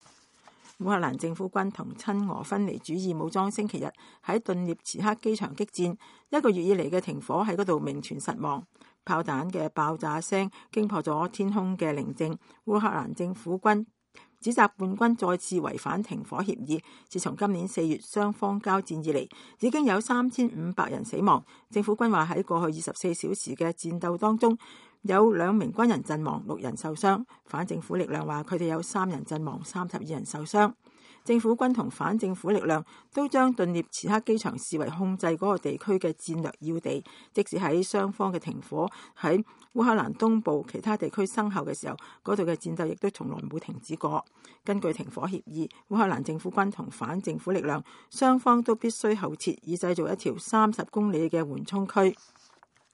烏克蘭政府軍同親俄分離主義武裝星期天在頓涅斯克機場激戰，一個月以來的停火在那裡名存實亡。炮彈的爆炸聲撕裂天空的寧靜。